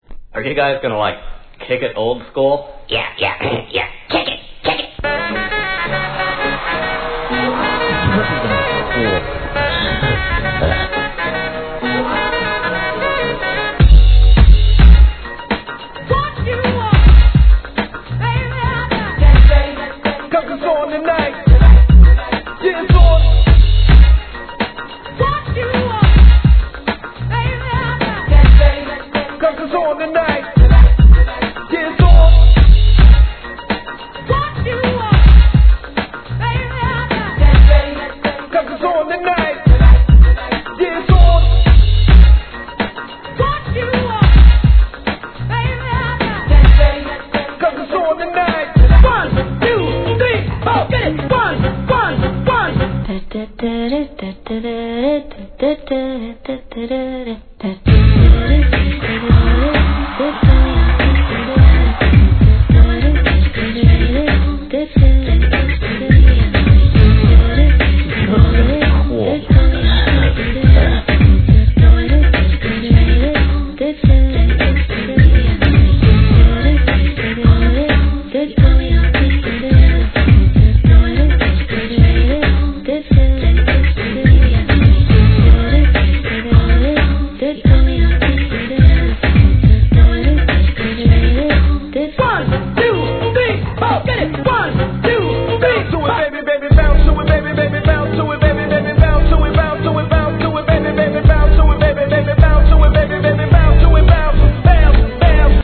HIP HOP/R&B
M,EGA MIXスタイルで盛り上げるPARTY BREAKS!!!